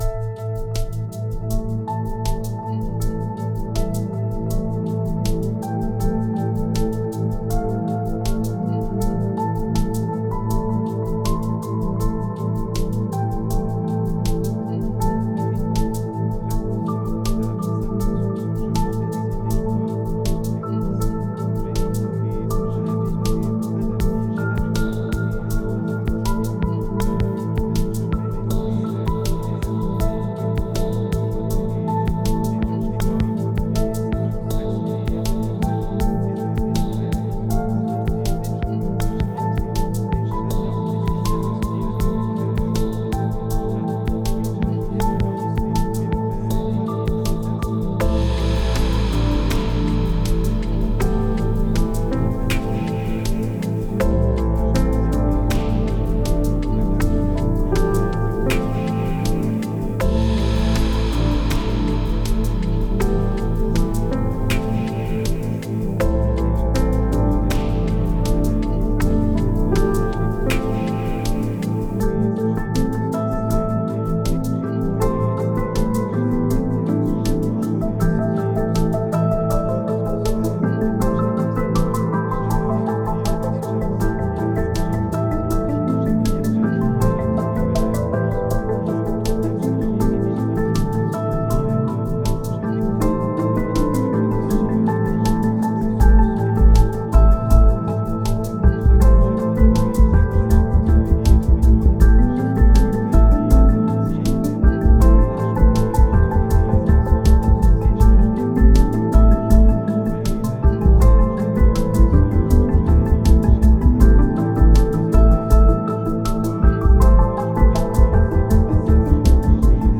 (Version RELAXANTE)
Alliage ingénieux de sons et fréquences curatives, très bénéfiques pour le cerveau.
Pures ondes thêta apaisantes 4Hz de qualité supérieure.
SAMPLE-Instant-present-2-apaisant.mp3